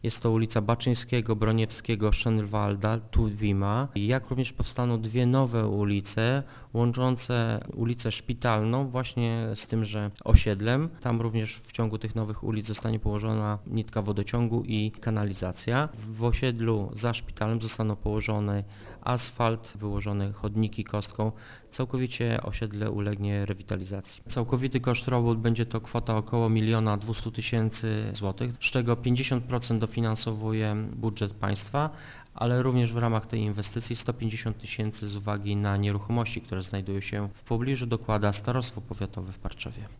- Teraz prace są prowadzone w rejonie osiedla "Za szpitalem - mówi burmistrz Parczewa Paweł Kędracki: